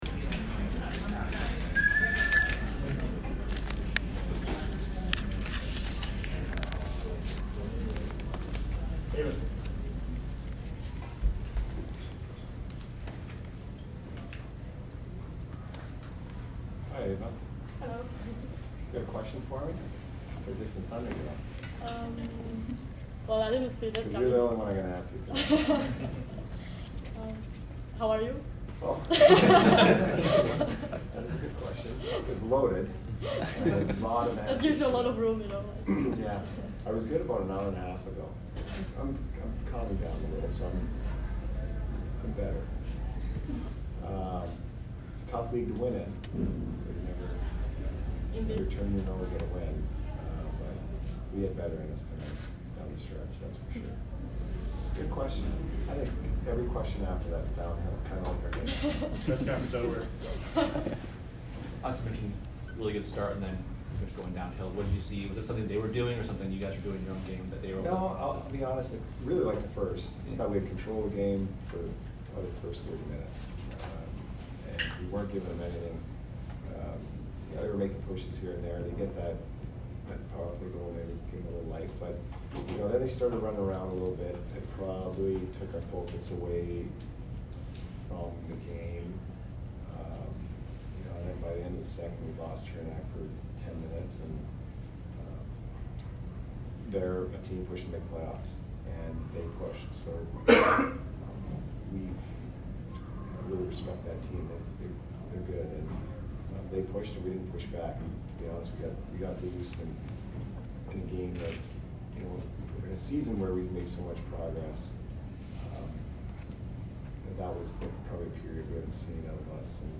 Jon Cooper post-game 2/15